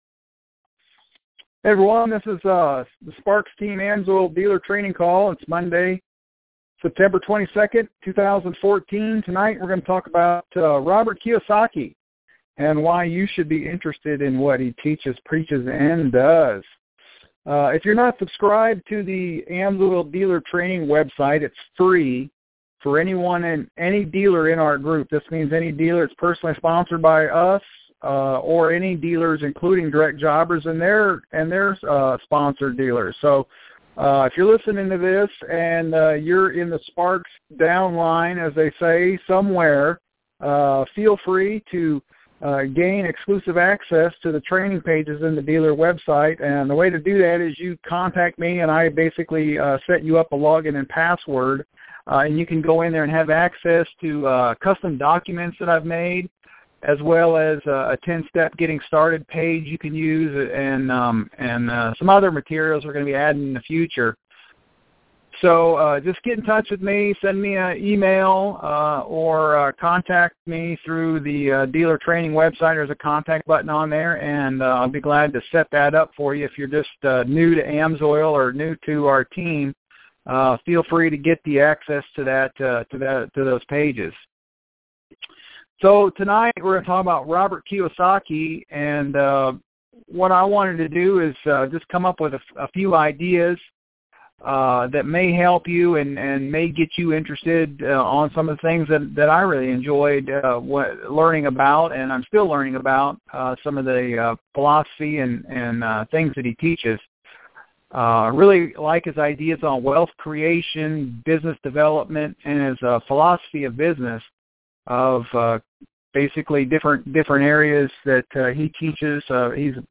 AMSOIL Dealer training call. Listen to this weeks call where we discuss Robert Kiyosaki and why smart entrepreneurs read his books.